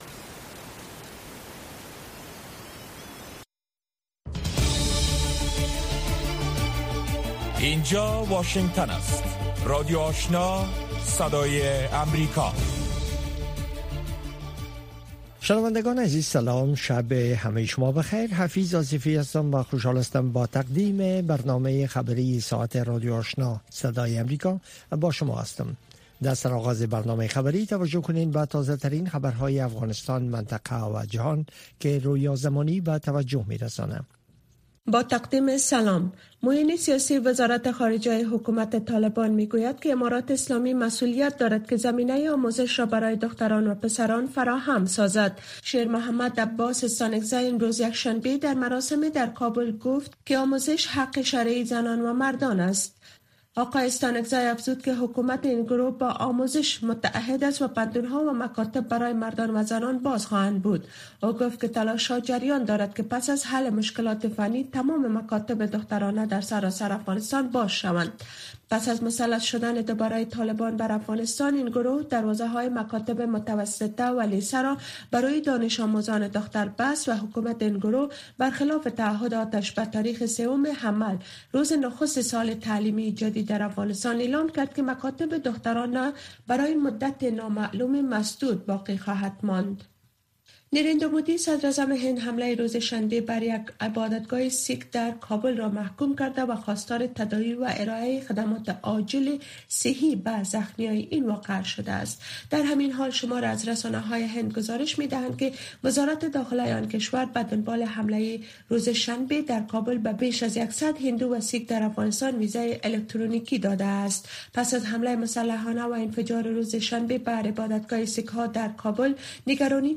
ماښامنۍ خبري خپرونه